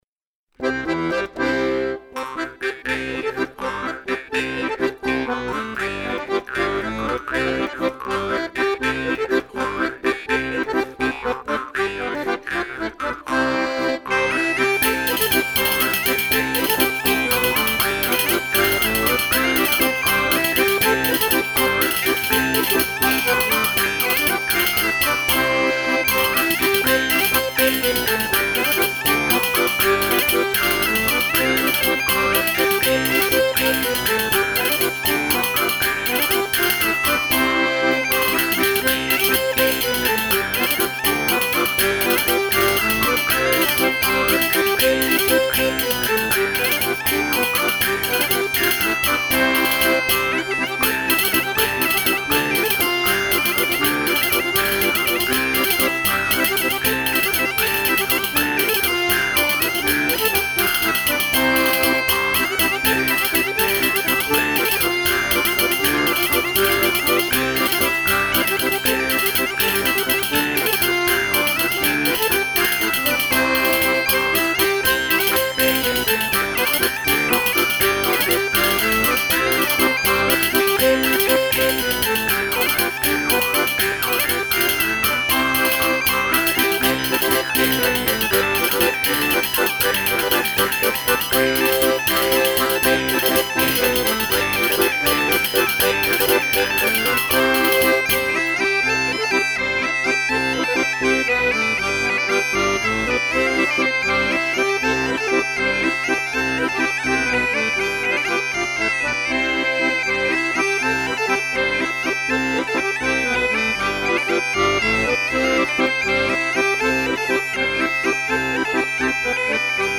Oiartzungo Lezoti estudioan grabatuta.